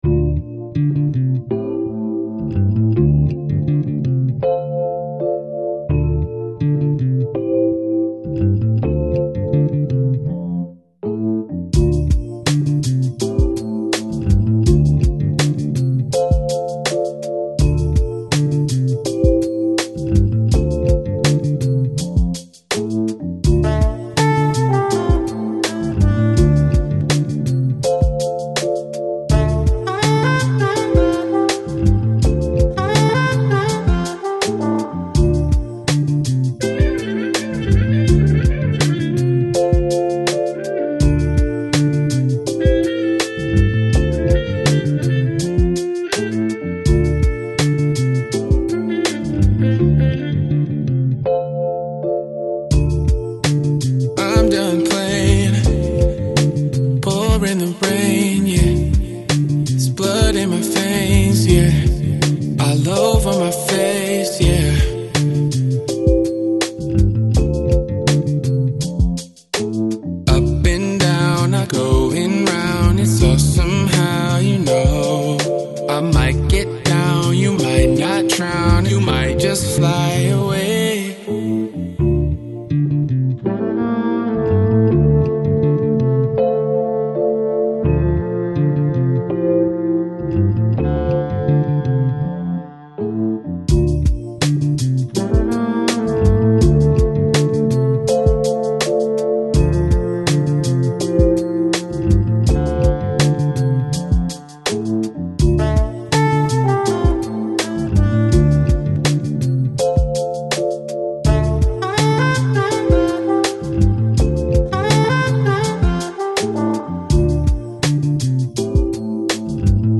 Жанр: Lo-Fi, Lounge, Chill Out, Downtempo